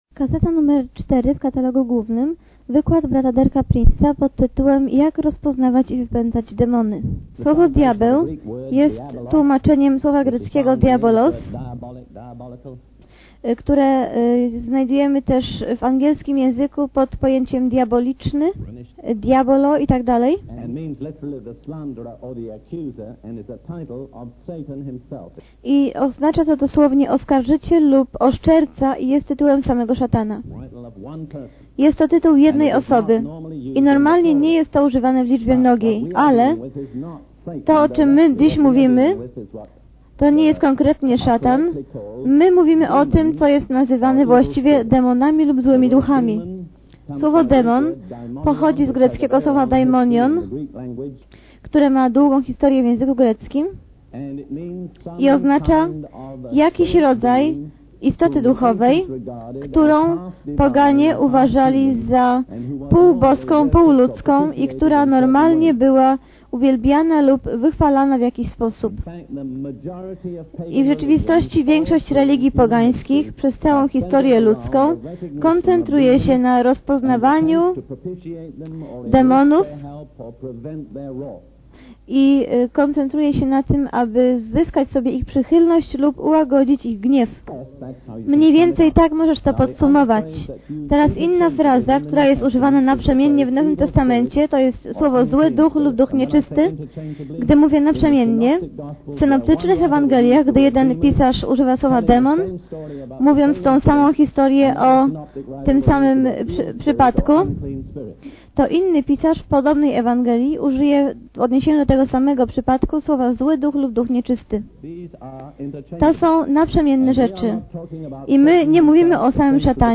Tytuł: DP-49 Jak rozpoznac demony Mówi: Nauczyciel Pisma Sw.